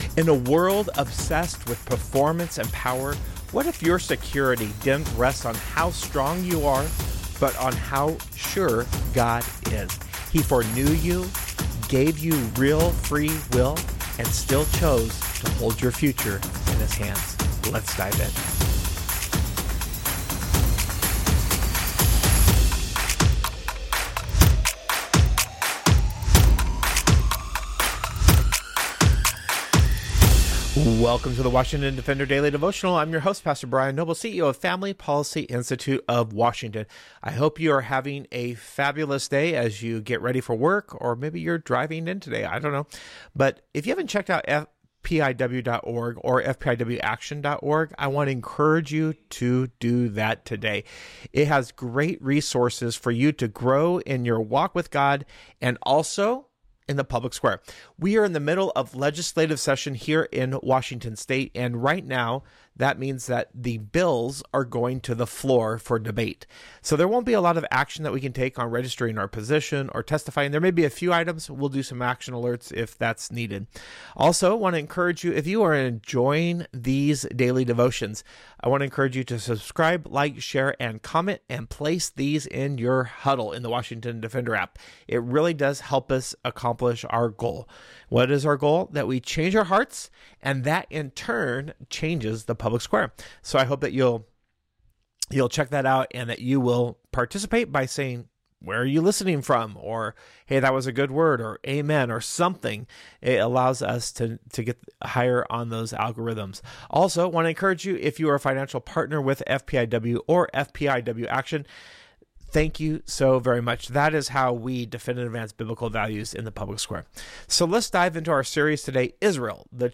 A Devotion for your drive into work: